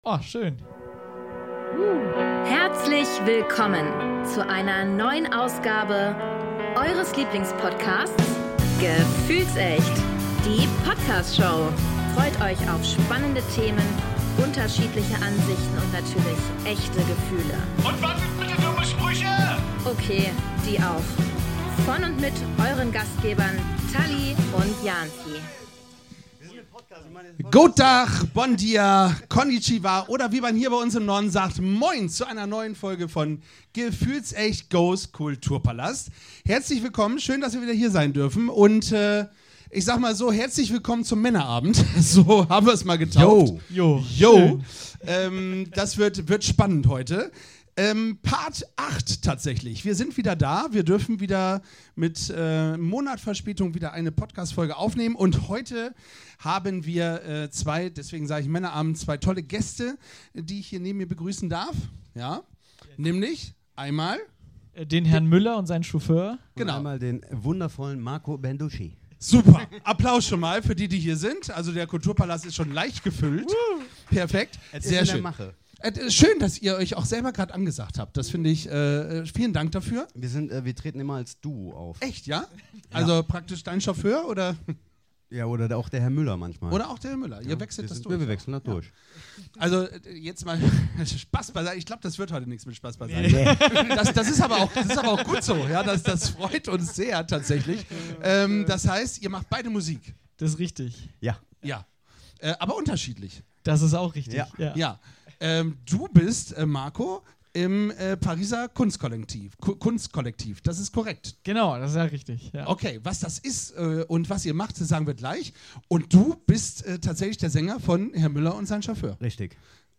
Ein runder Männerabend, wenn man so will.